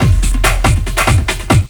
LOOP48--01-R.wav